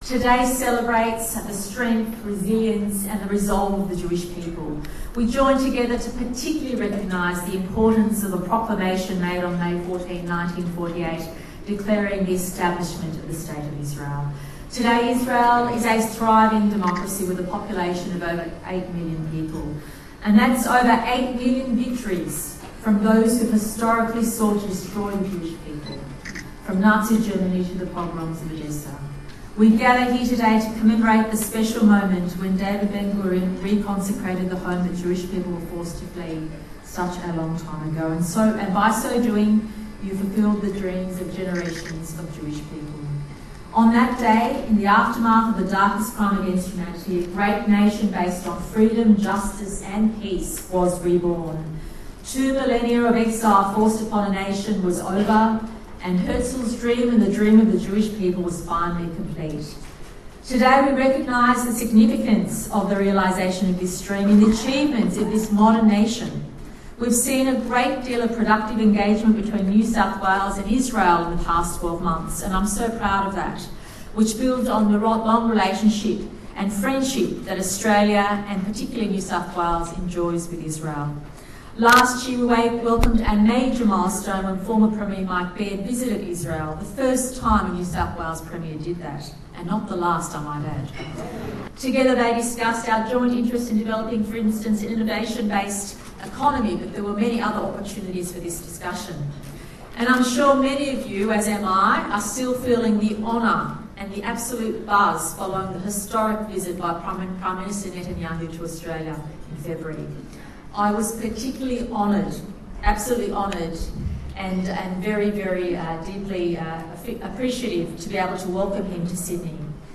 NSW Premier Gladys Berejiklian at a major function for Yom Haatzmaut, Israels 69th Day of Independence.with community leaders and politicians.